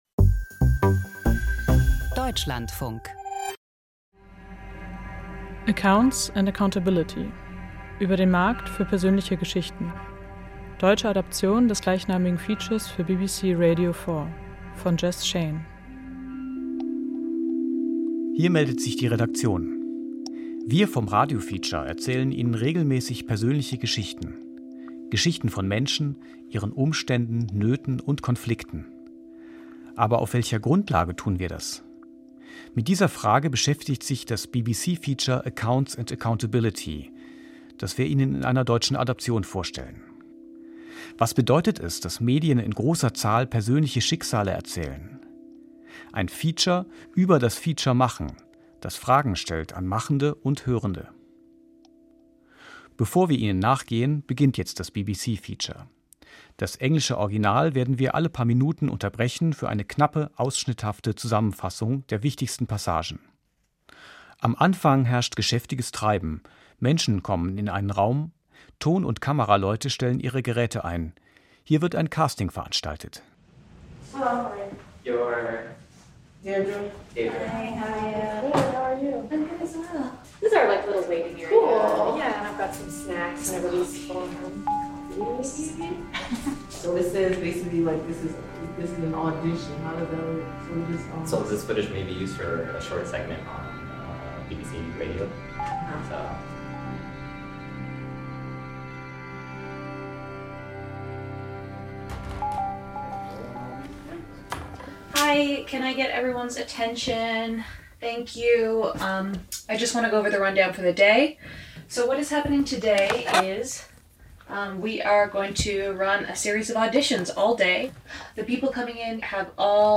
Feature Archiv Ruanda